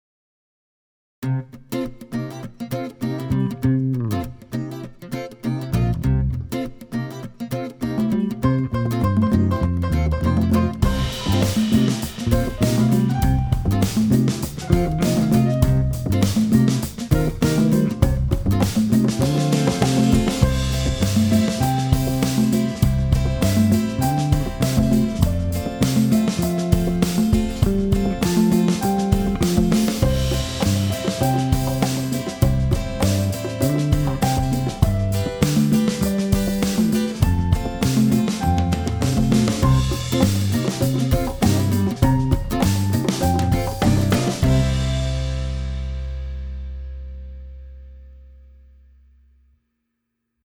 Happy, Hopeful - Podcast Intro / Blues / Acoustic